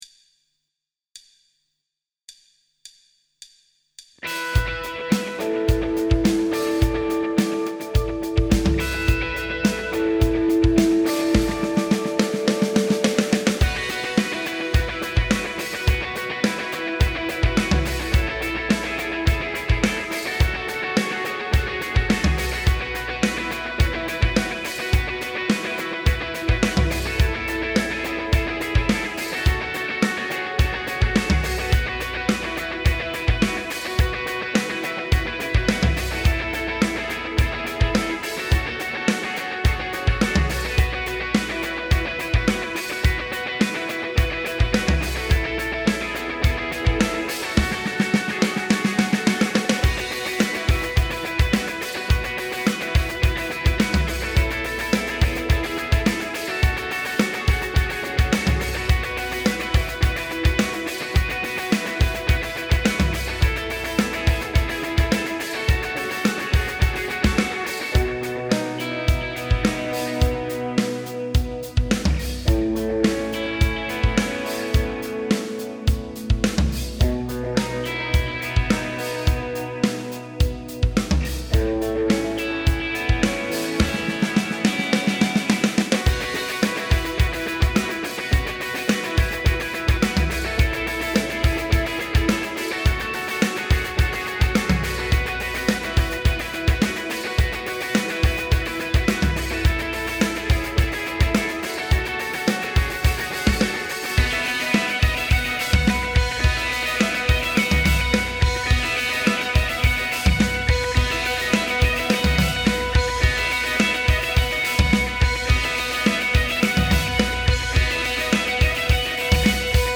BPM : 106
Tuning : E
Without vocals
Based on the album version